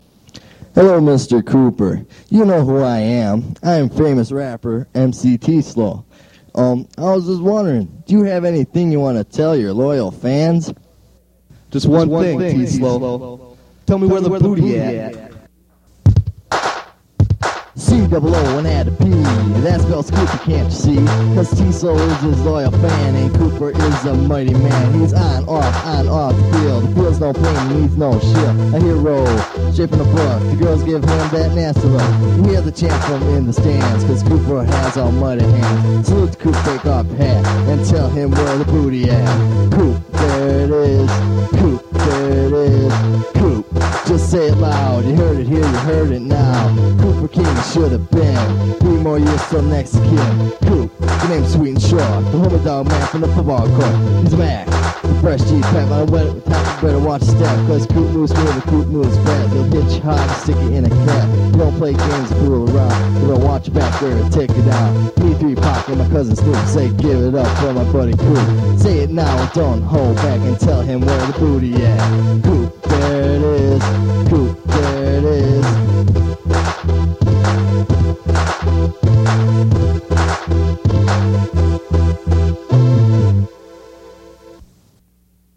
hip hop and rap
masterful bass bumping beats